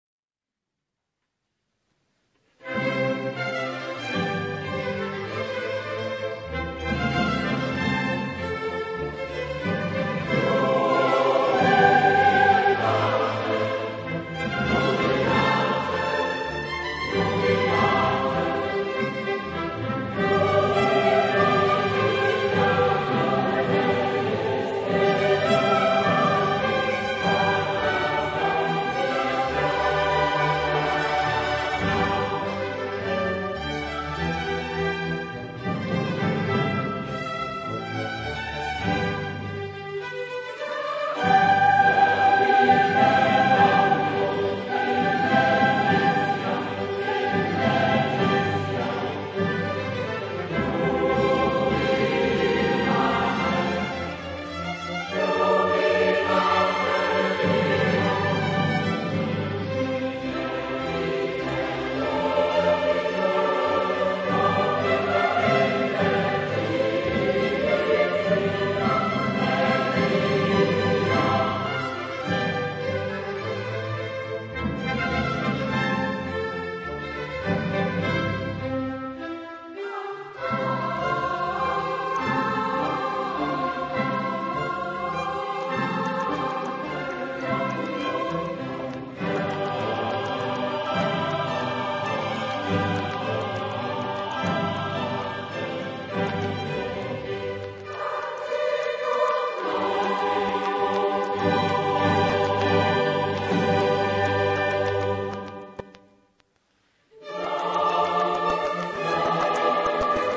SATB (4 voix mixtes) ; Partie(s) instrumentale(s) ; Partition choeur seul ; Partition complète.
Psaume.
Classique.
Consultable sous : JS-18e Sacré avec instruments